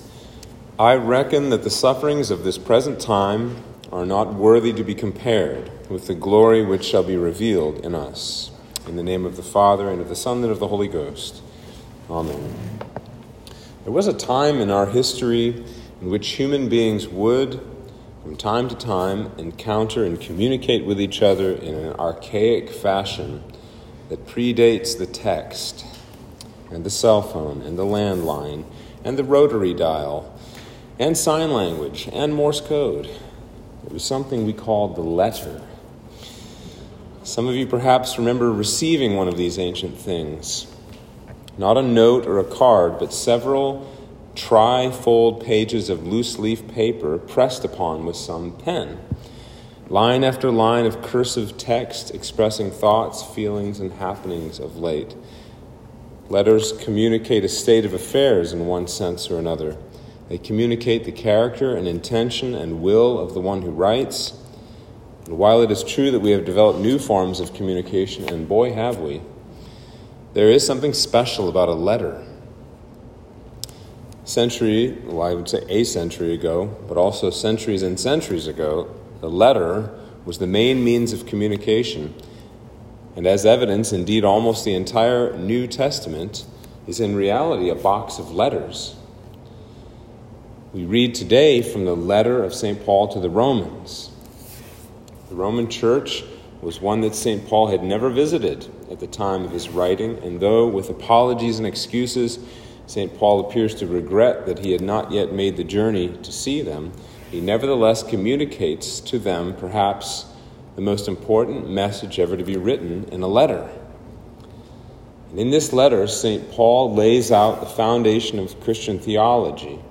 Sermon for Trinity 4